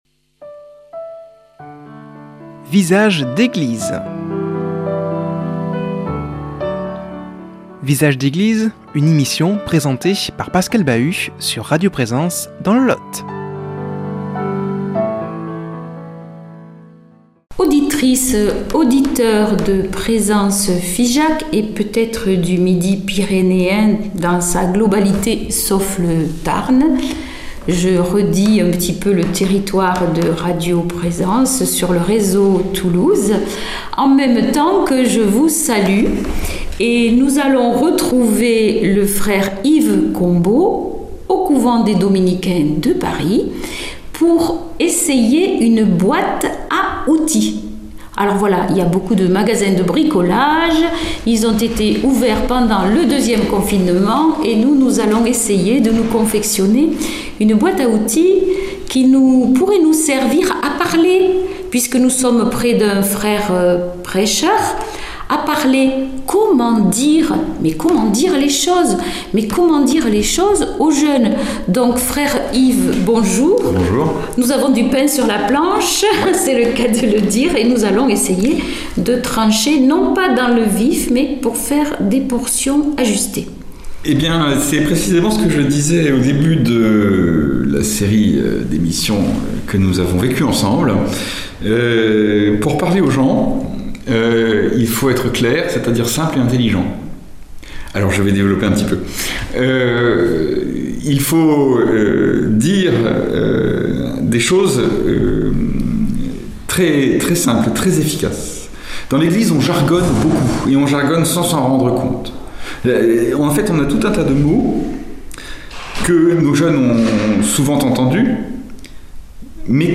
au couvent des dominicains de Paris